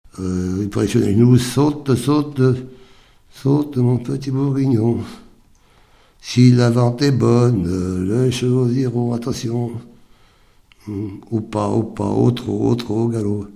Mémoires et Patrimoines vivants - RaddO est une base de données d'archives iconographiques et sonores.
formulette enfantine : sauteuse
Pièce musicale inédite